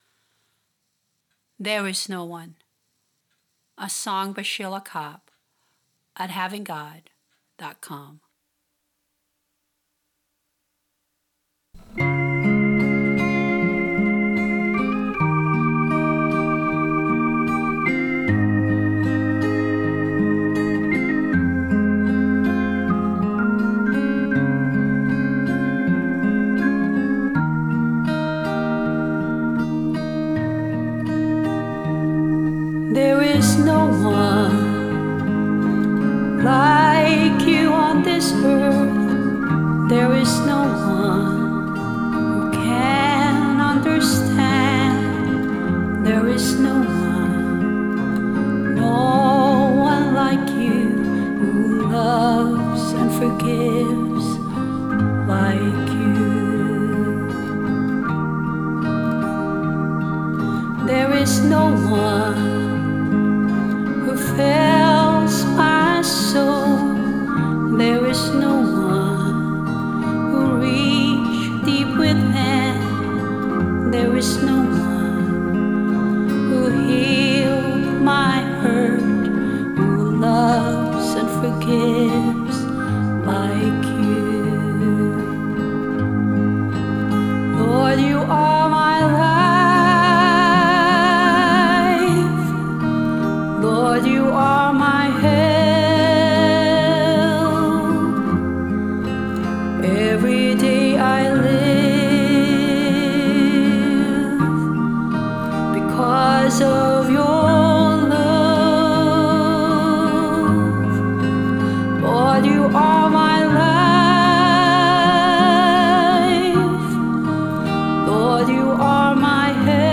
Vocals, guitar, and bass
Organ, keyboard and strings